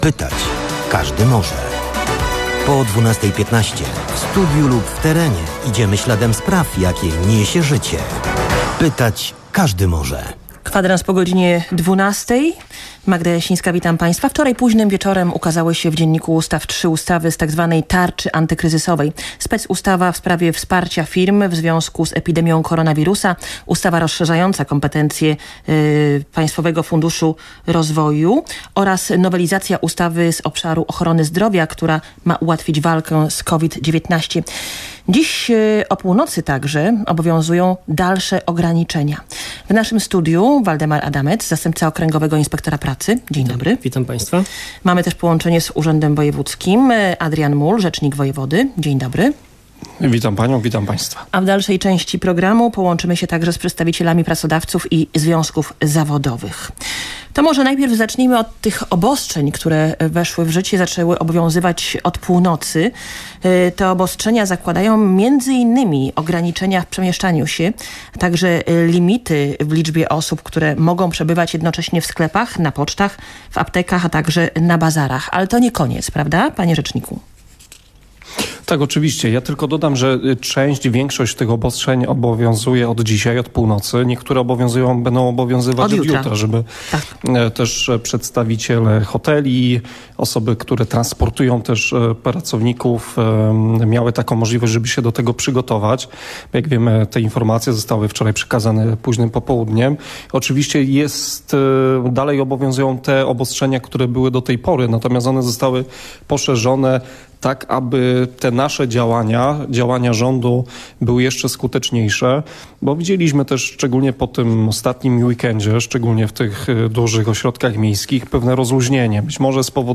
wypowiadają się związkowcy